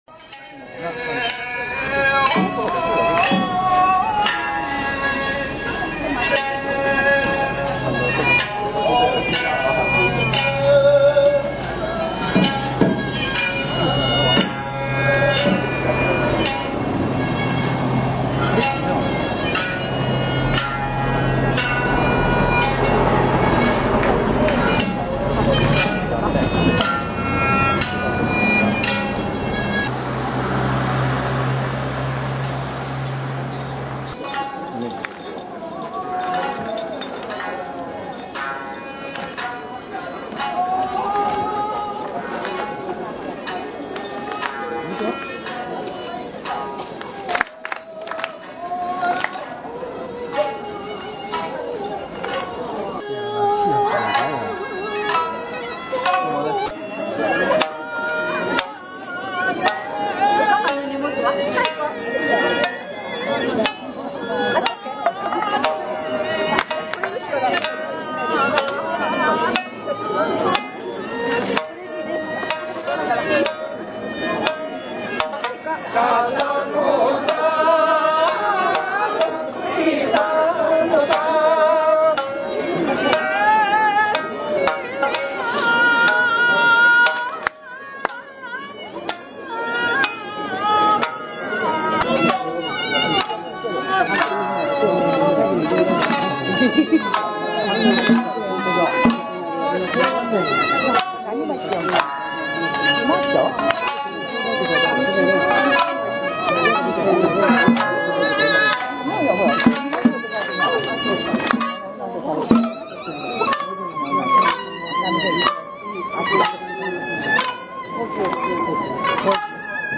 場　　　所 ： 富山県八尾市街
哀愁を帯びた「胡弓」の音色が、風多き山里越中八尾の風土をじょうじょうと歌い上げます。